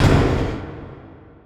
snd_locker_ch1.wav